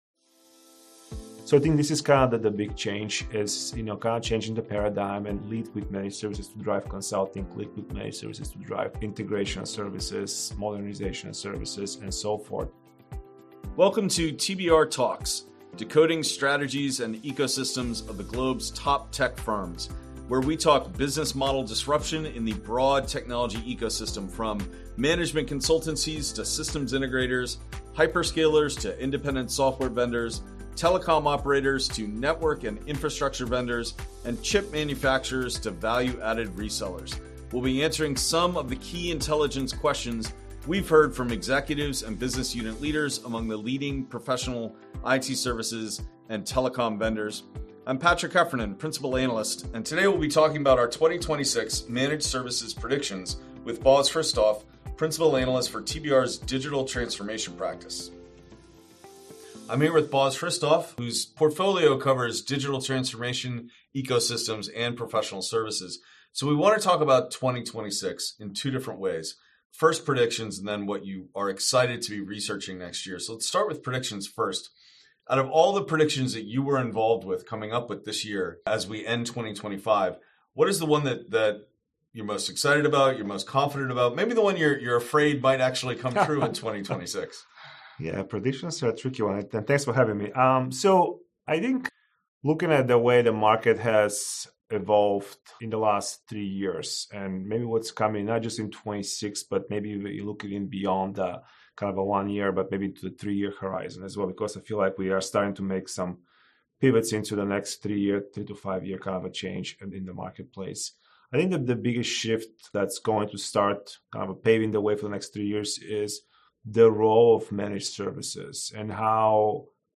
The conversation explores a fundamental shift underway in managed services: from a cost-optimization and labor-arbitrage model to a growth-oriented, insight-driven entry point for consulting, integration and modernization services.